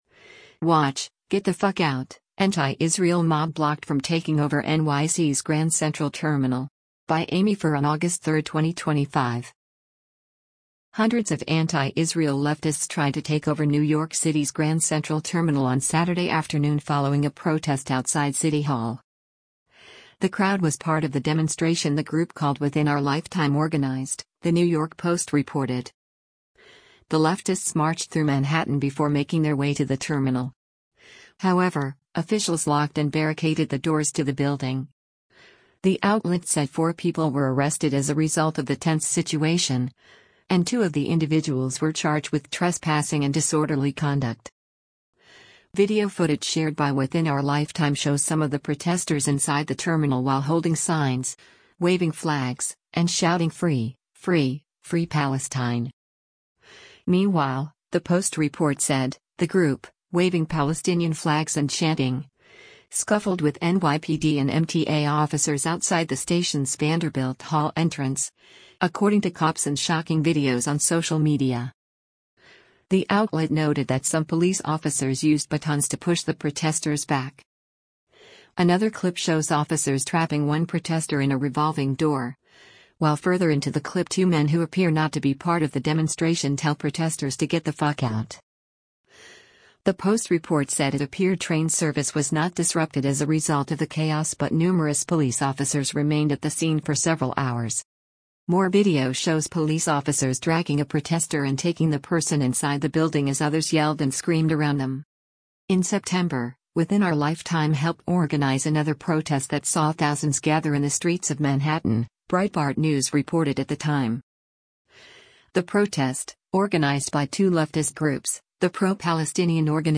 Video footage shared by Within Our Lifetime shows some of the protesters inside the terminal while holding signs, waving flags, and shouting “Free, free, free Palestine!”:
More video shows police officers dragging a protester and taking the person inside the building as others yelled and screamed around them: